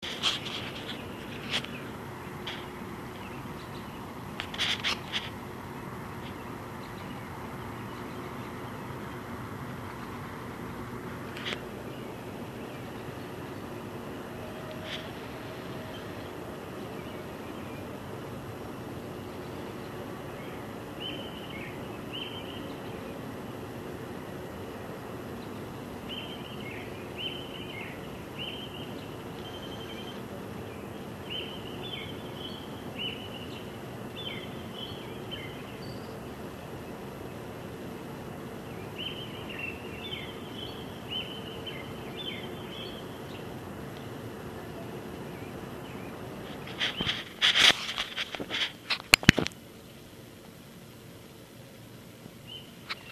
Field Recording: Birds Chirping outside of Enterprise Hall as heard near the treetops from the 3rd floor (my room) at 4AM (because I couldn’t sleep).
Birds-Chirping.mp3